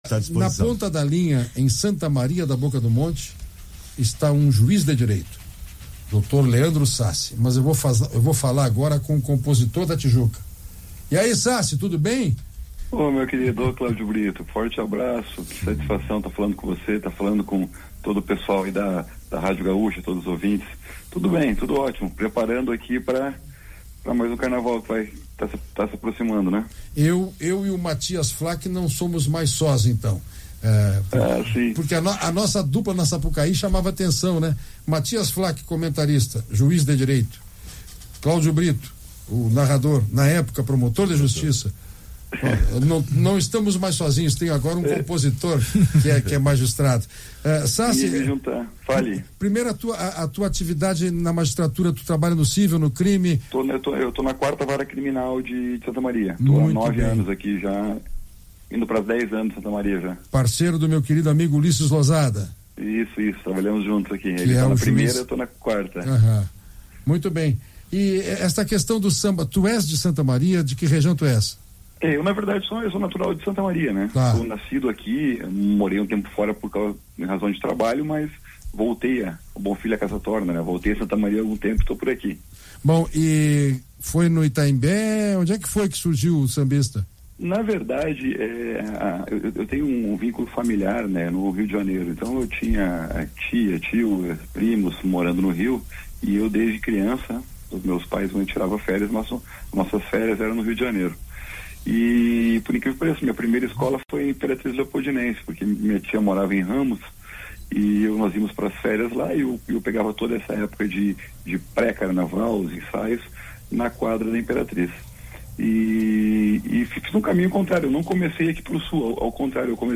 Magistrado concede entrevista abordando sua história como sambista